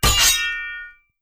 Melee Sword Sounds
Melee Weapon Attack 18.wav